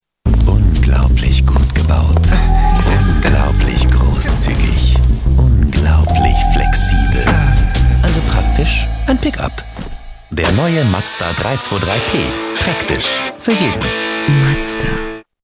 deutscher Sprecher und Schauspieler.
Kein Dialekt
Sprechprobe: eLearning (Muttersprache):
voice over talent german